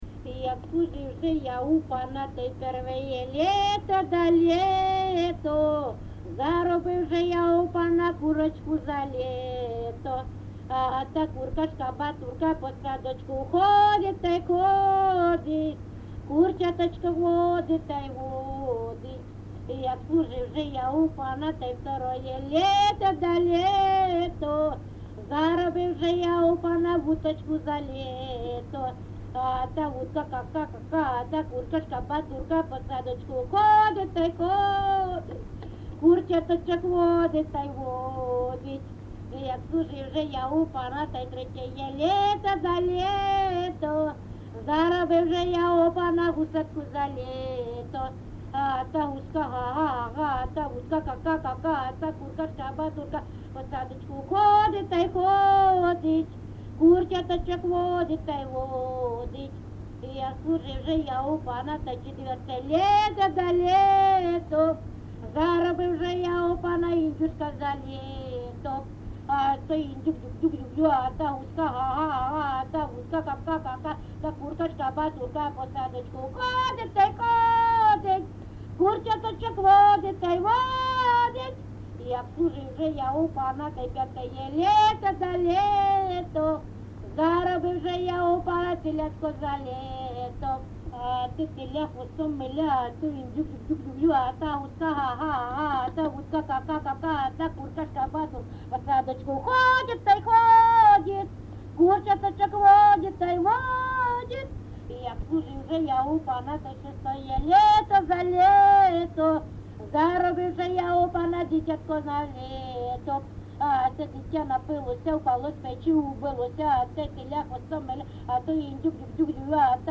ЖанрЖартівливі
Місце записус. Гнилиця, Сумський район, Сумська обл., Україна, Слобожанщина